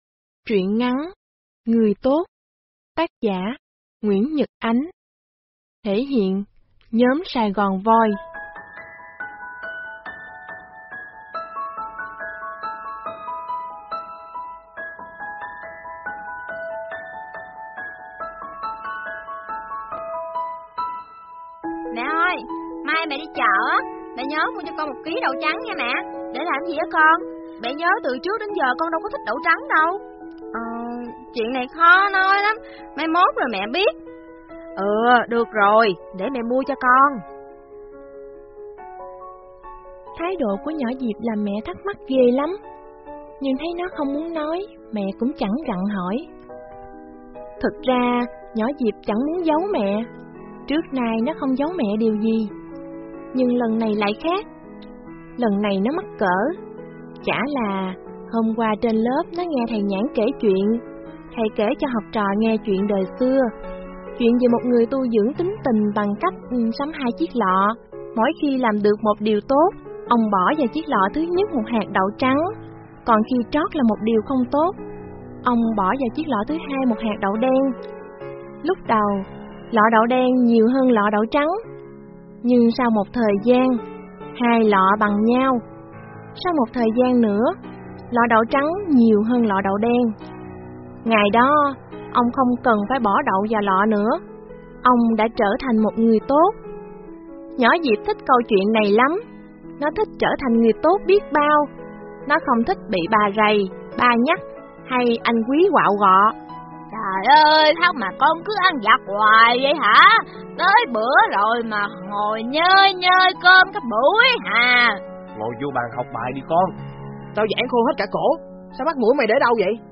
Sách nói | Người Tốt